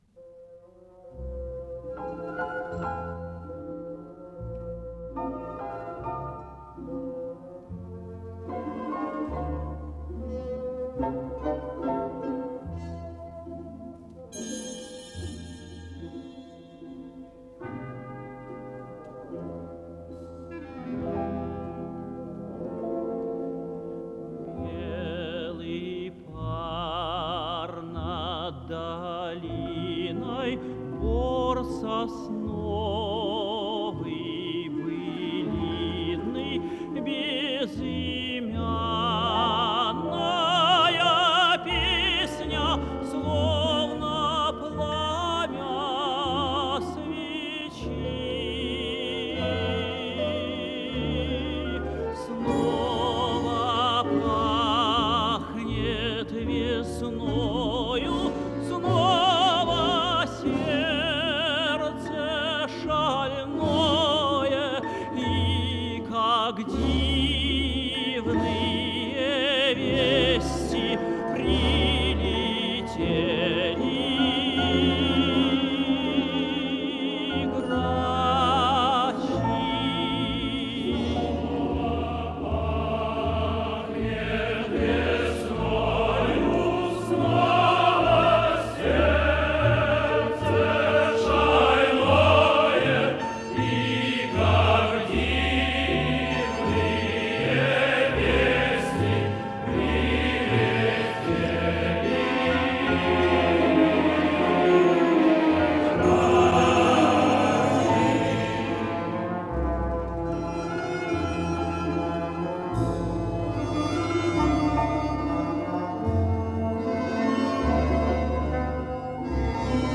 с профессиональной магнитной ленты
Скорость ленты38 см/с